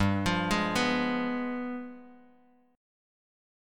G7b9 Chord
Listen to G7b9 strummed